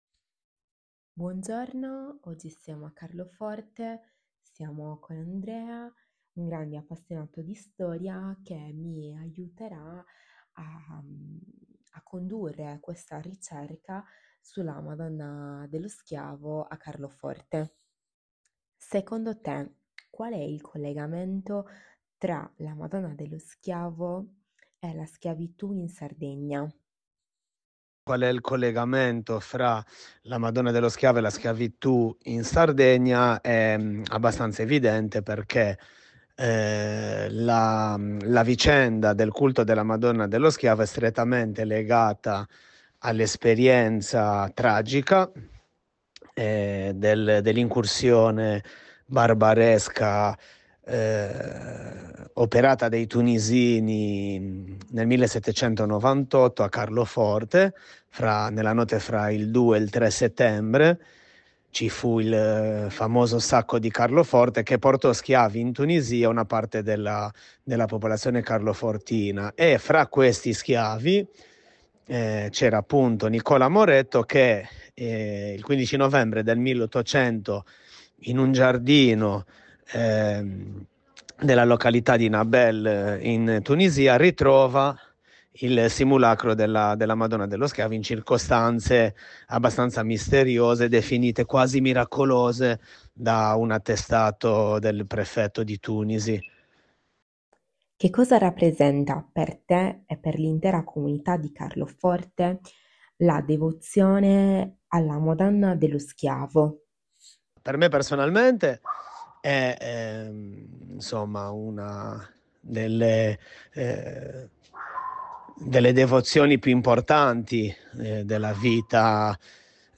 Intervista
Apparecchiatura di registrazione Microfono e cellulare